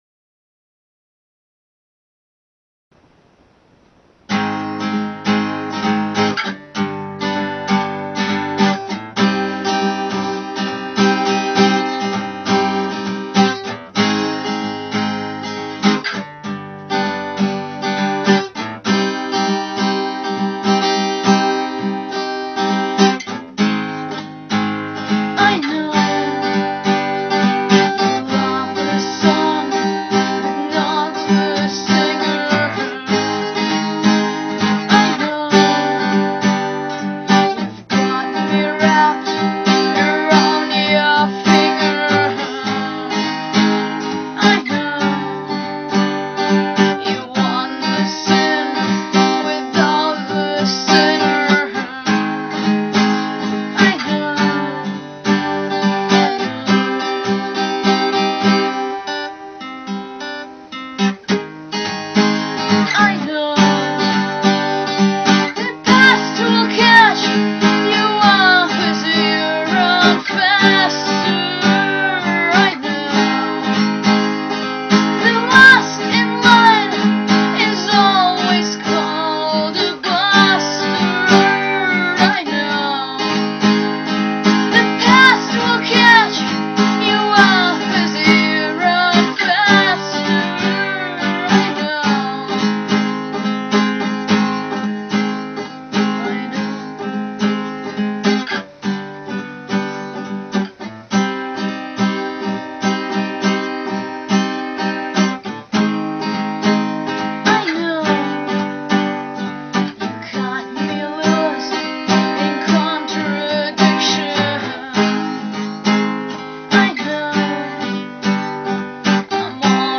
cover, request